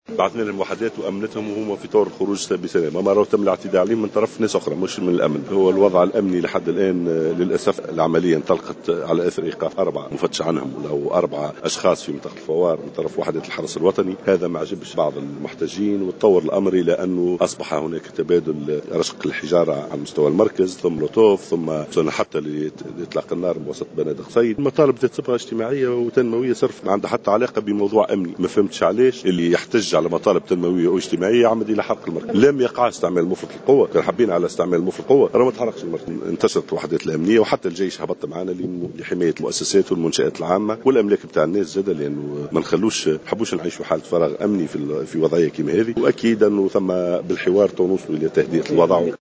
قال وزير الداخلية محمد ناجم الغرسلي في تصريح ل"جوهرة أف أم" إن الوضع في معتمدية الفوار تطوّر إلى حد رشق مركز الحرس الوطني بالحجارة والزجاجات الحارقة وإطلاق النار من خلال بنادق صيد ثم حرق مقر مركز الحرس.